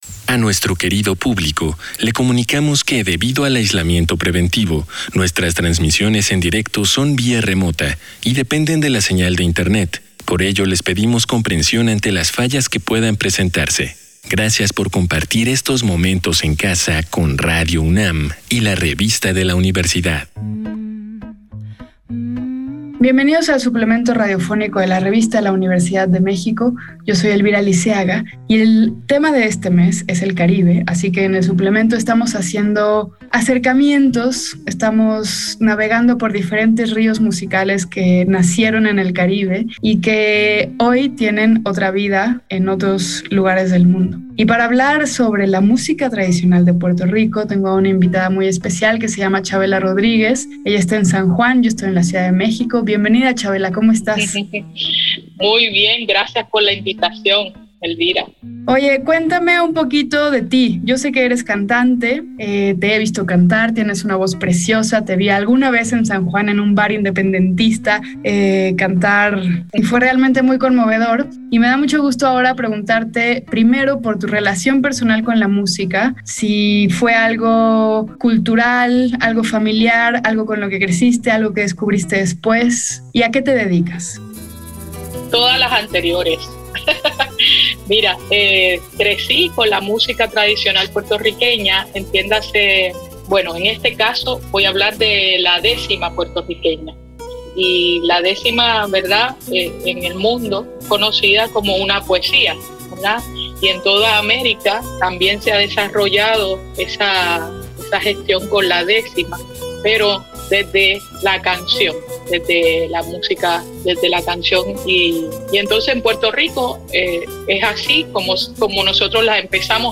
Fue transmitido el jueves 12 de agosto de 2021 por el 96.1 FM.